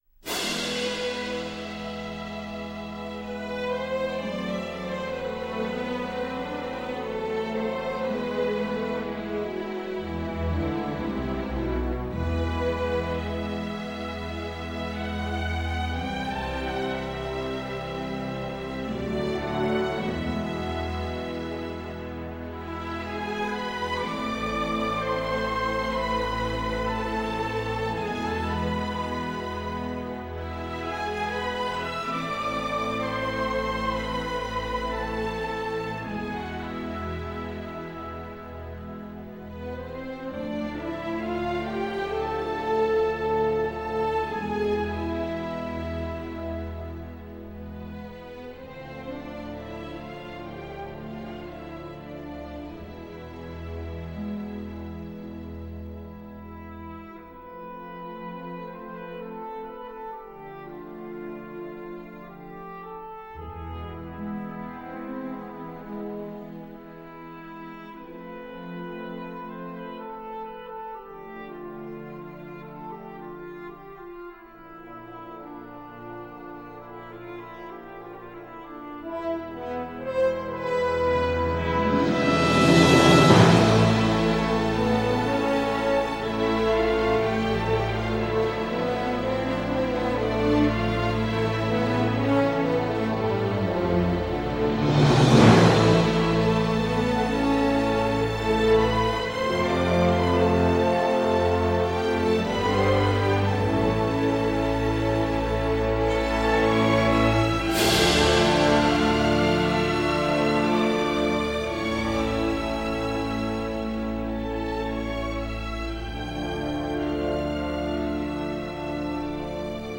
Cliché et rentre-dedans mais très divertissant.